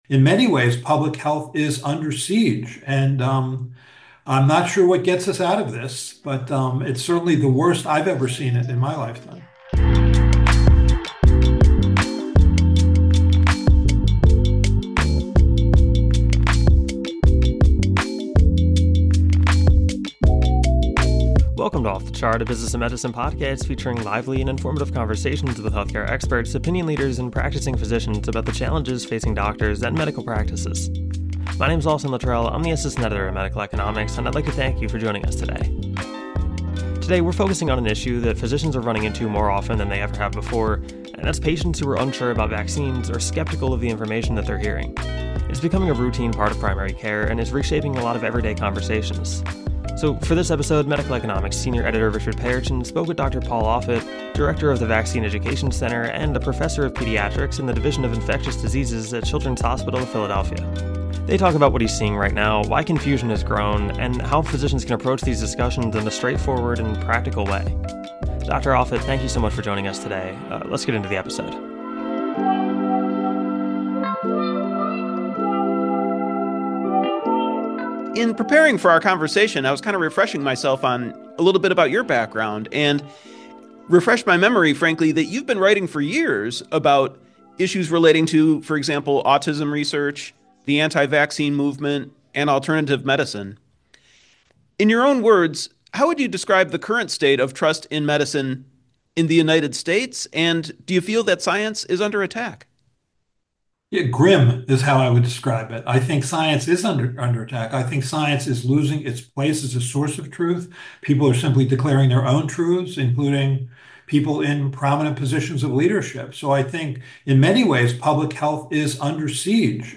This interview was conducted in preparation for Medical Economics November-December cover story, " Medicine under attack: How physicians can help their patients navigate the disinformation age. "